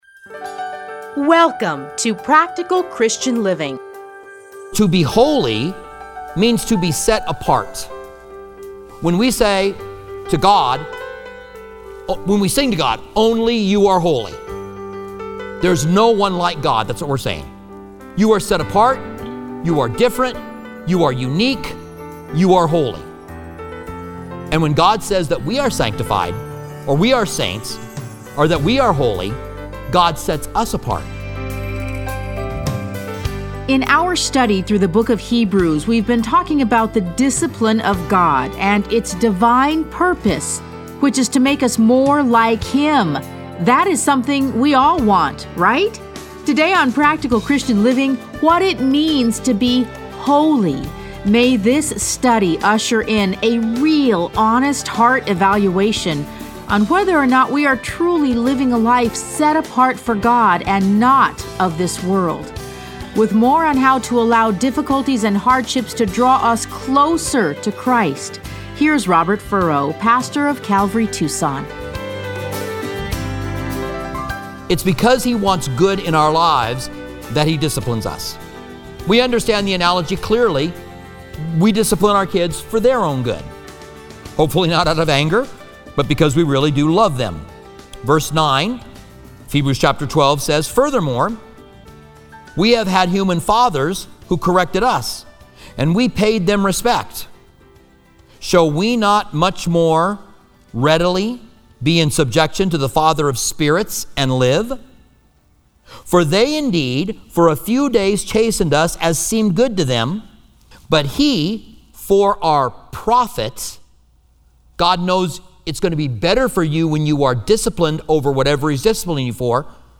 Listen to a teaching from Hebrews 12:3-11.